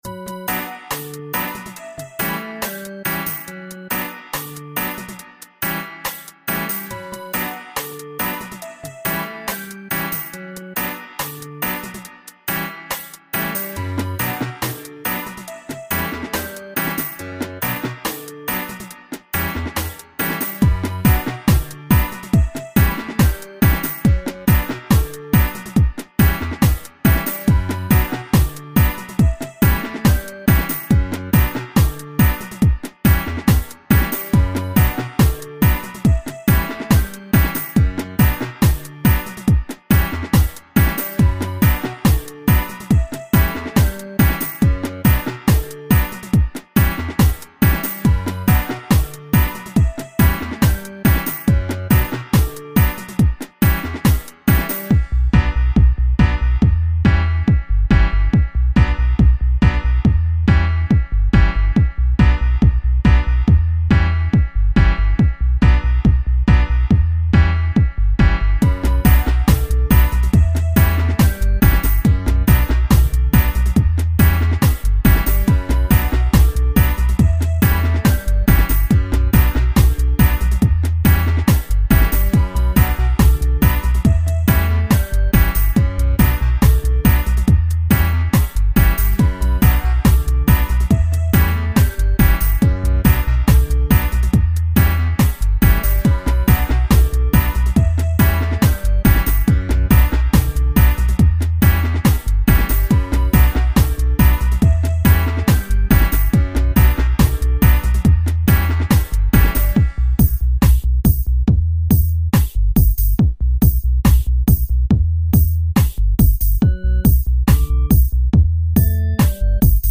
dubspanish-instru-ydXCu